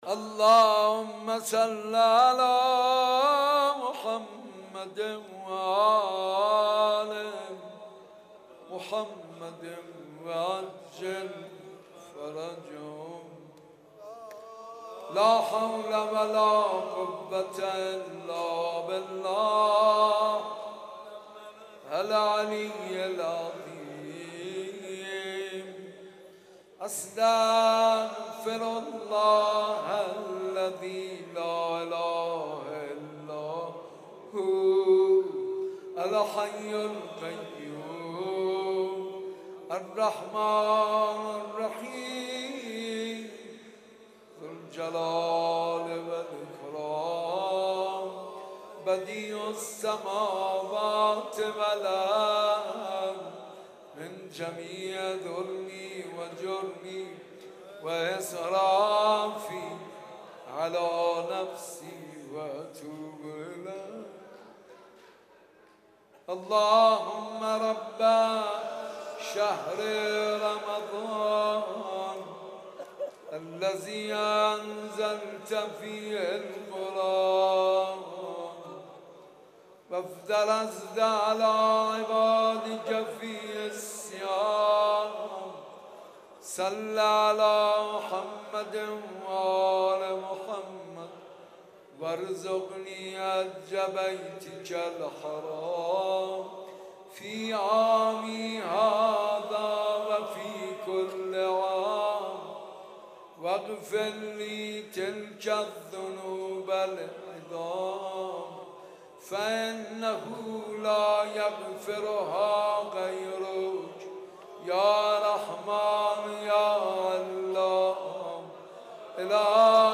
قرائت جزء هفتم، مناجات و دعای روز هفتم ماه مبارک رمضان - تسنیم